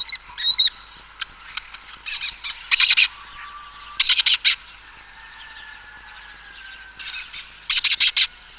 Lesser Kestrel
Lesser-Kestrel.mp3